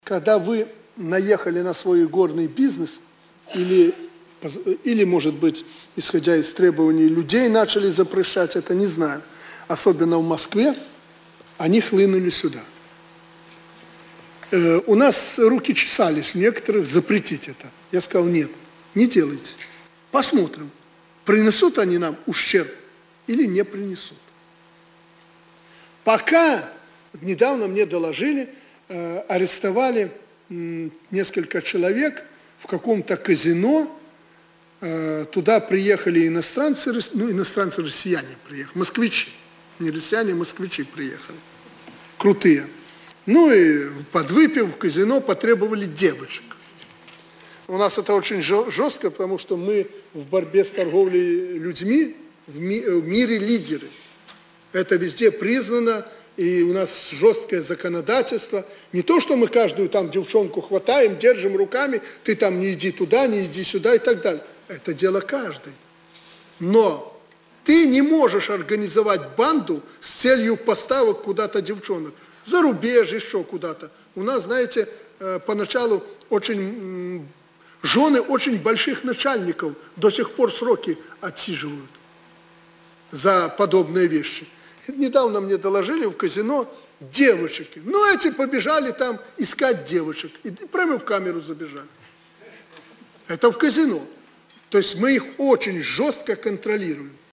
Прэсавая канфэрэнцыя Аляксандра Лукашэнкі для расейскіх журналістаў.